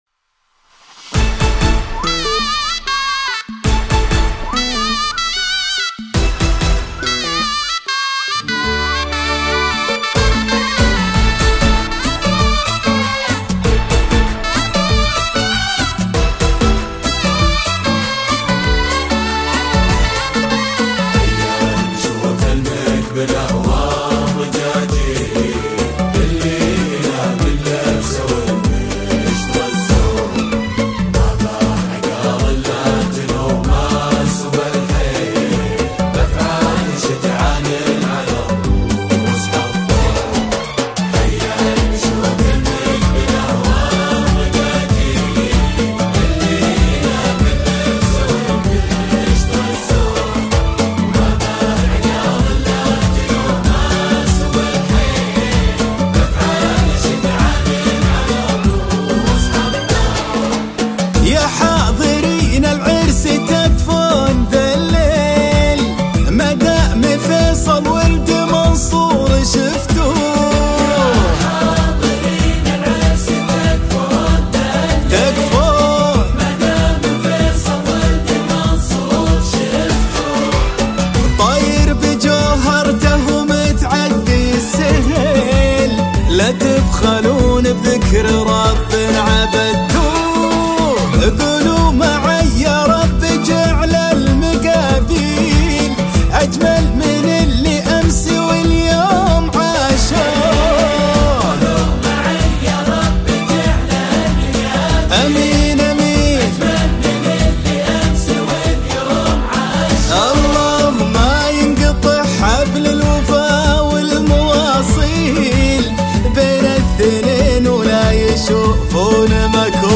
زفة عريس حماسيه